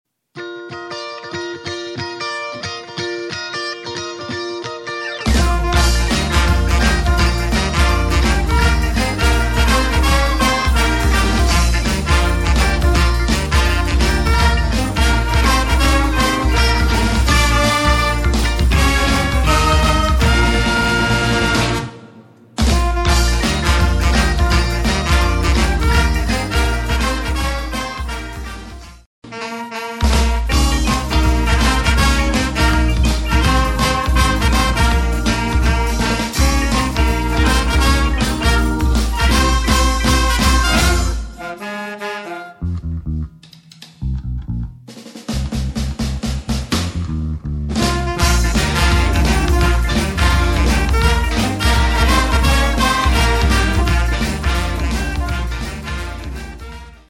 Street Band